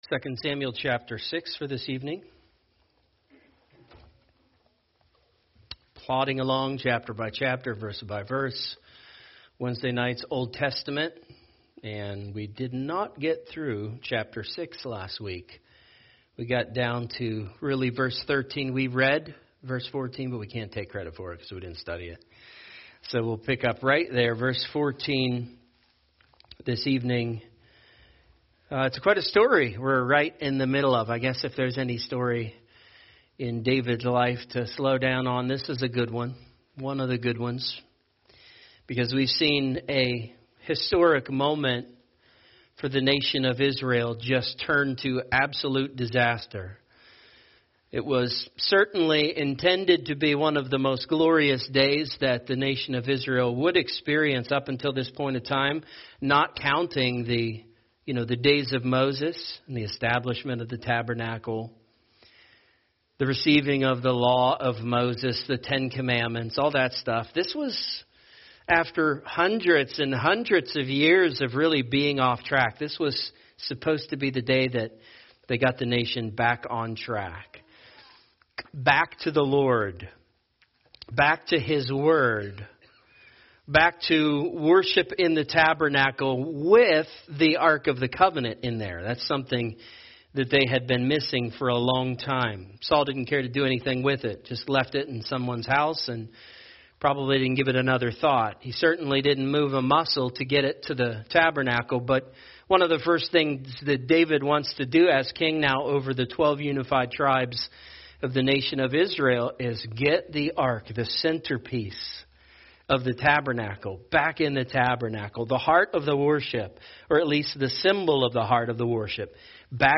A message from the topics "The Book of Exodus."